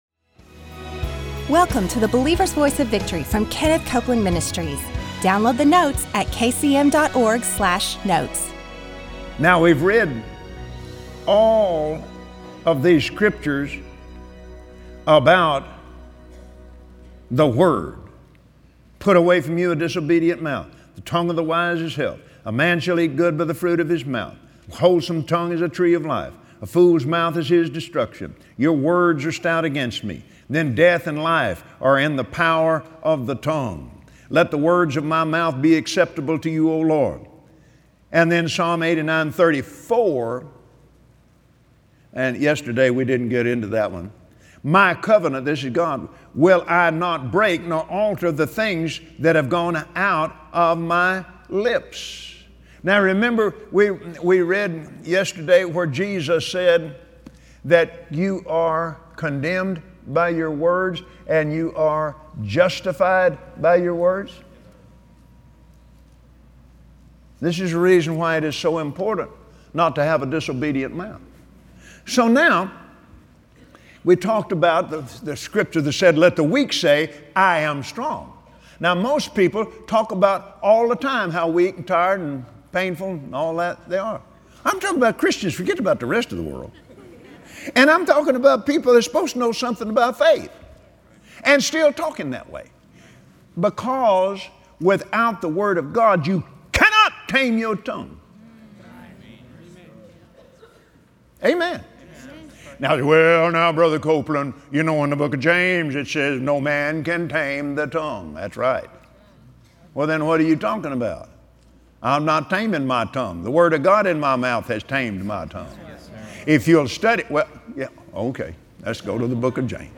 Believers Voice of Victory Audio Broadcast for Thursday 05/19/2016Today, on the Believer’s Voice of Victory, Kenneth Copeland teaches you the power of faith-filled words. Become a doer of The Word and use scripture as your foundation to “say it by faith”.